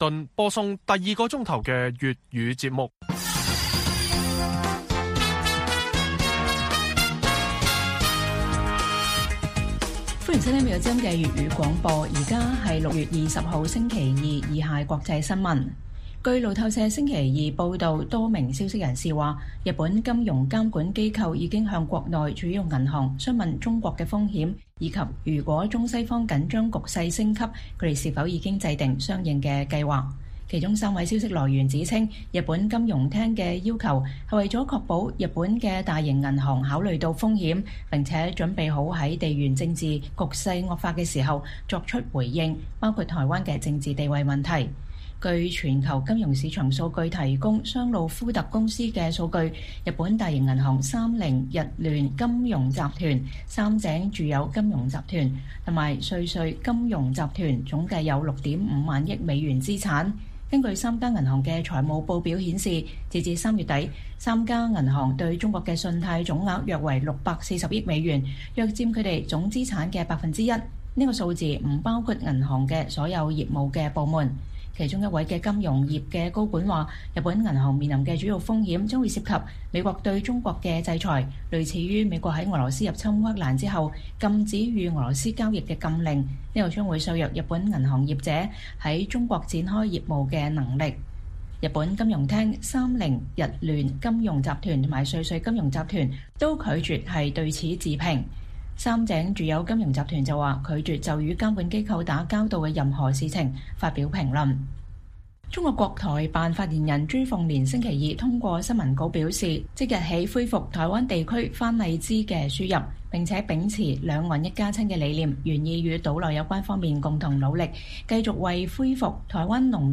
粵語新聞 晚上10-11點: 日本金融監管機構提醒銀行為潛在台海衝突制定因應方案